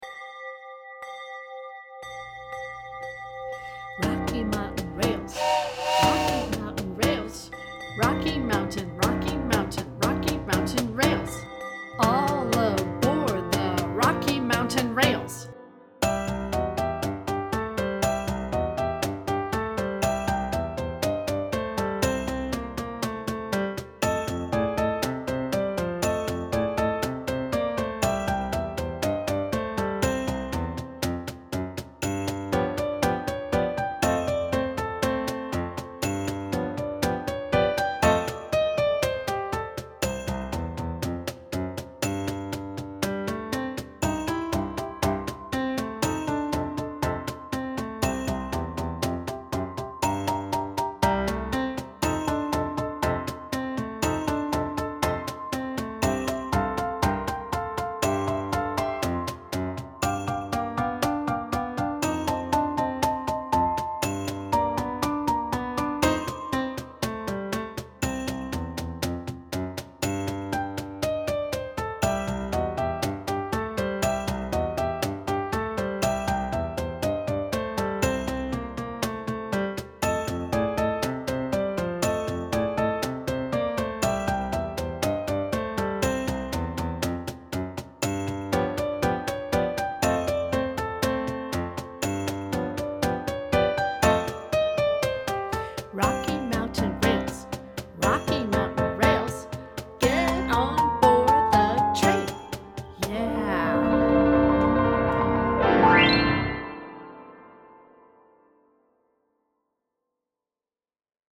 Time Signature: 4/4 (BPM = 210–240)